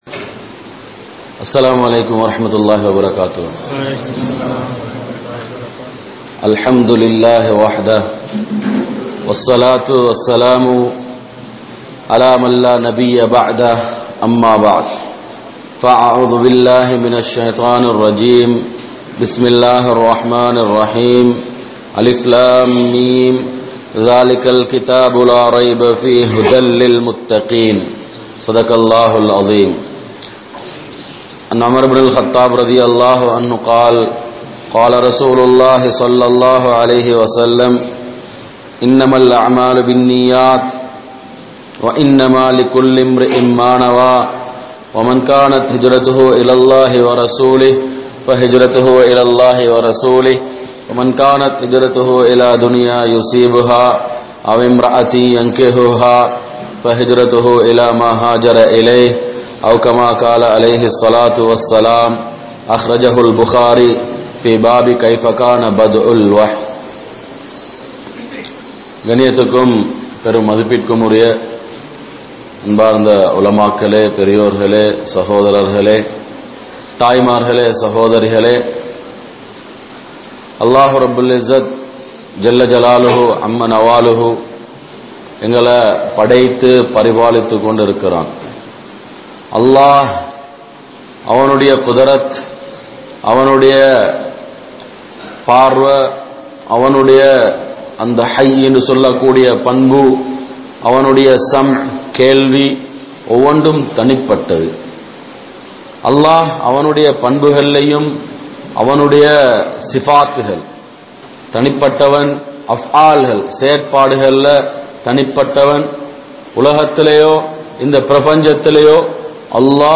Haraaththai Vida Mudiyaathavarhal (ஹராத்தை விட முடியாதவர்கள்) | Audio Bayans | All Ceylon Muslim Youth Community | Addalaichenai